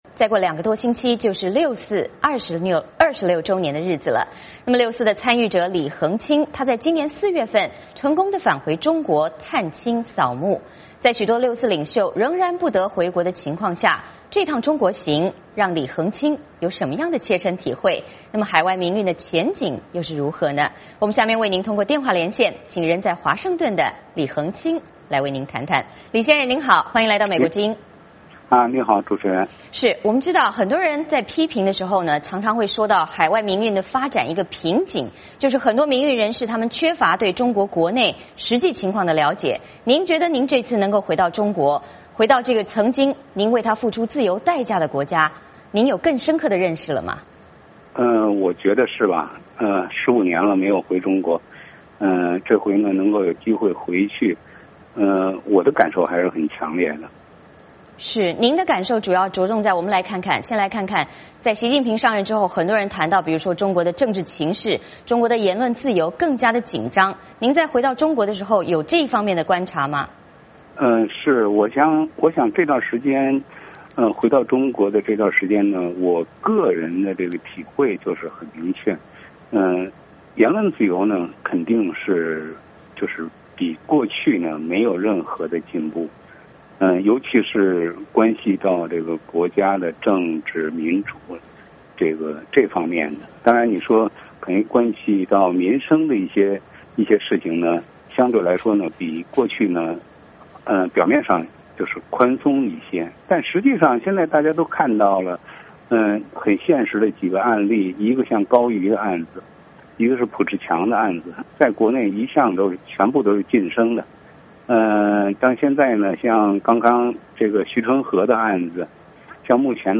下面我们通过电话连线